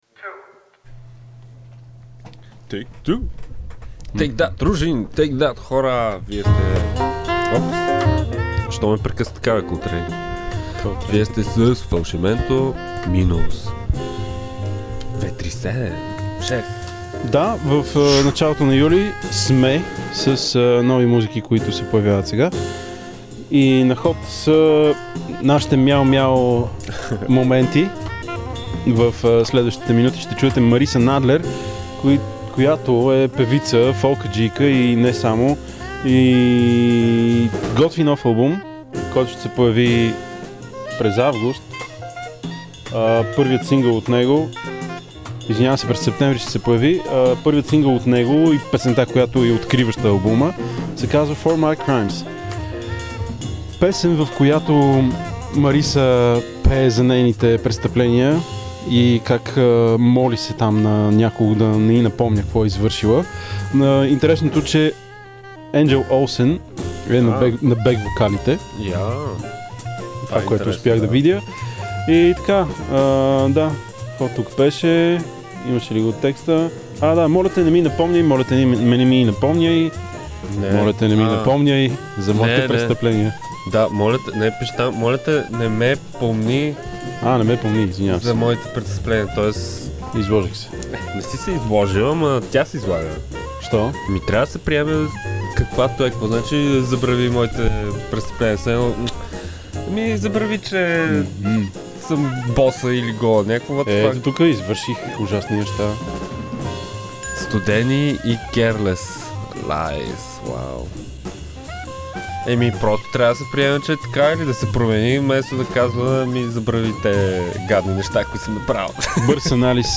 Жанр: Blues.